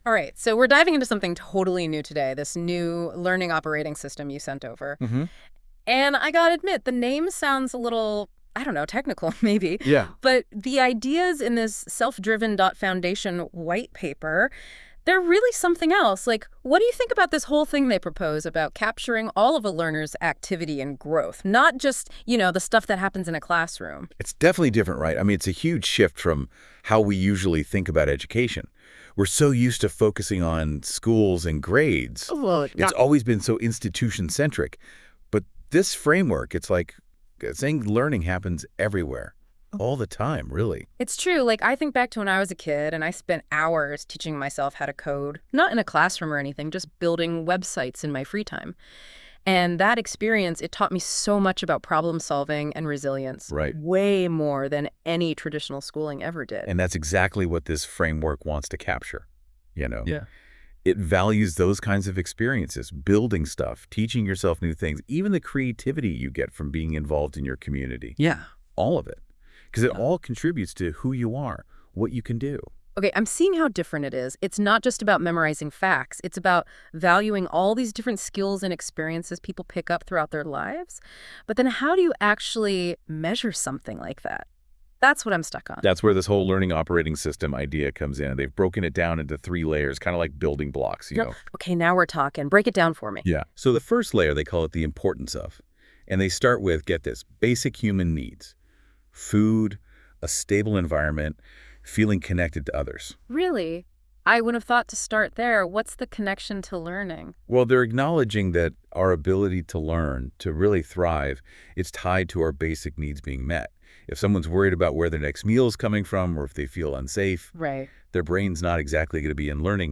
Experimental conversion of the White Paper (pdf) into a podcast episode using the Google NotepadLM Gen AI app.
selfdriven-whitepaper-podcast-ai-generated.wav